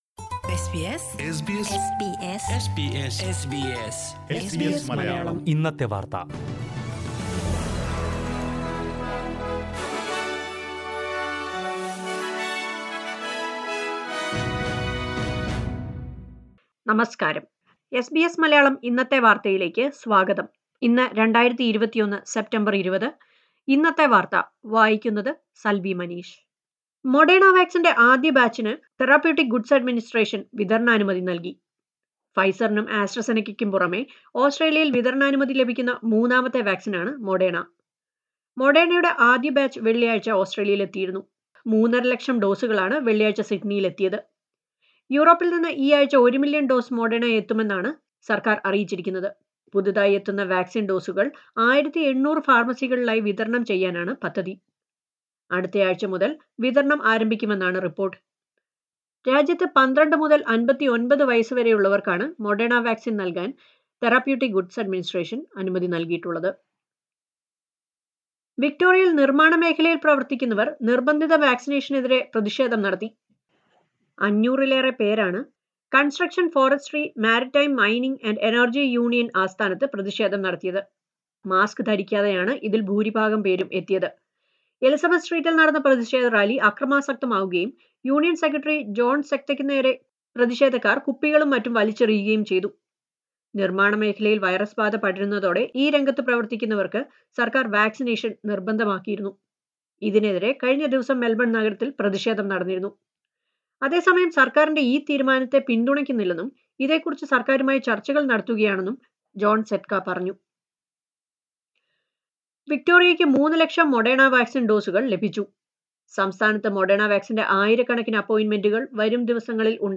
2021 സെപ്റ്റംബർ 20ലെ ഓസ്ട്രേലിയയിലെ ഏറ്റവും പ്രധാന വാർത്തകൾ കേൾക്കാം...